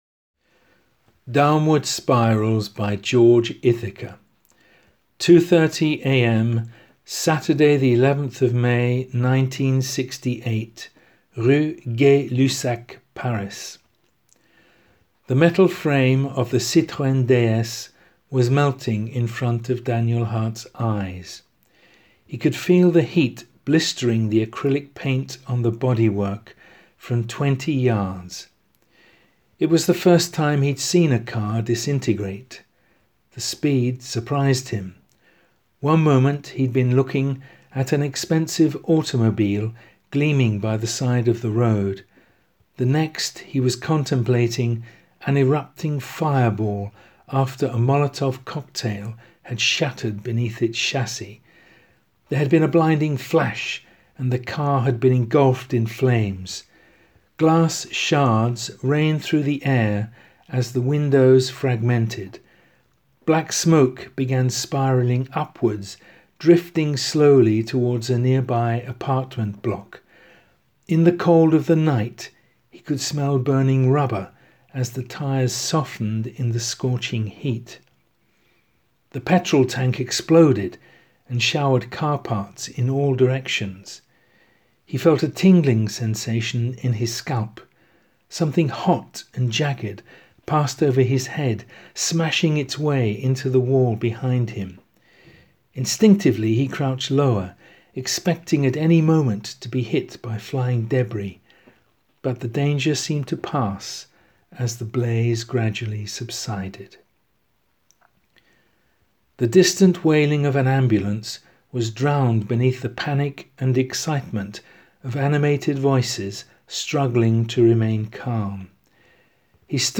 Spoken text